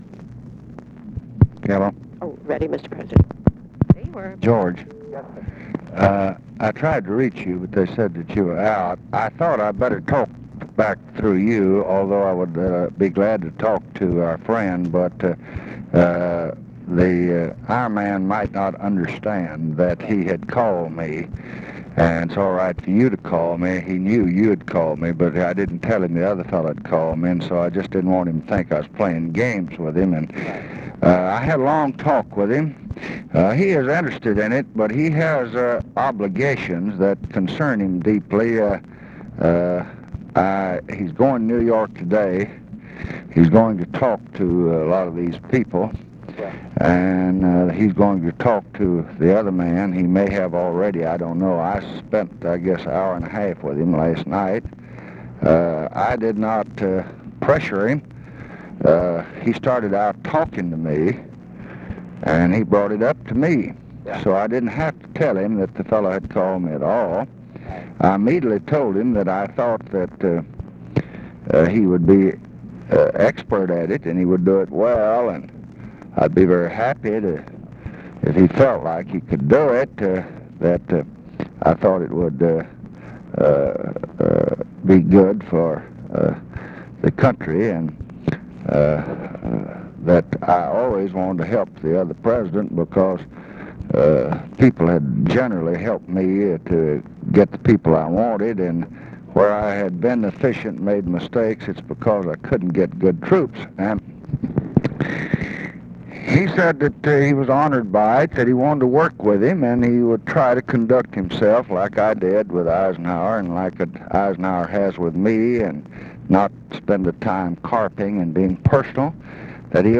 Conversation with GEORGE SMATHERS, November 23, 1968
Secret White House Tapes